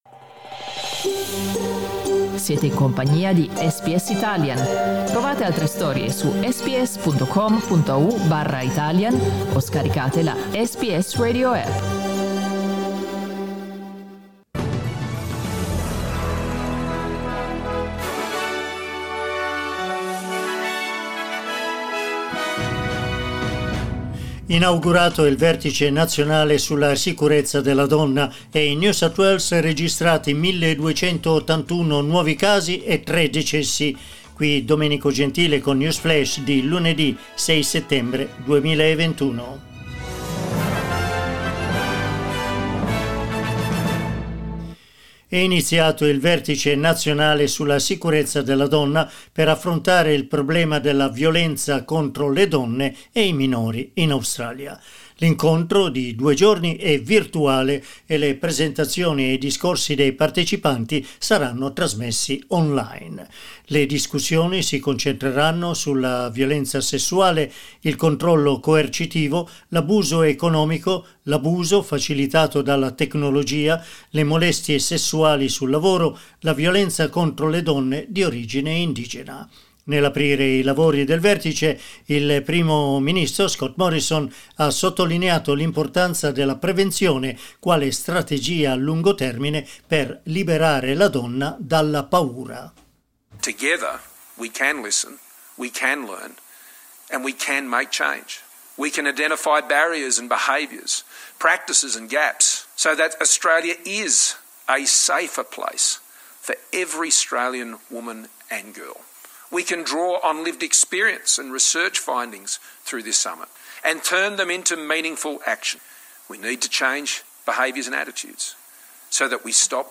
News Flash lunedì 6 settembre 2021
L'aggiornamento delle notizie di SBS Italian.